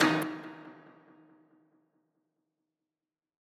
Perc (Maybach).wav